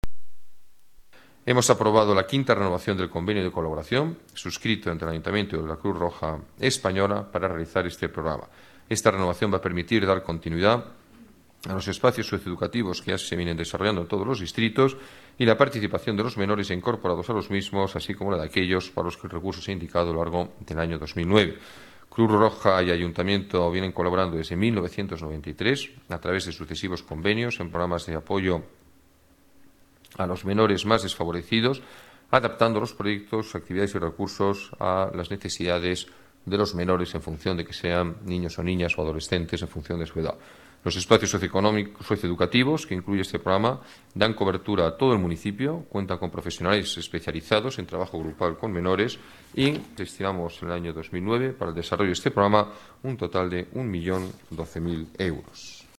Nueva ventana:Declaraciones alcalde, Alberto Ruiz-Gallardón: espacios socioeducativos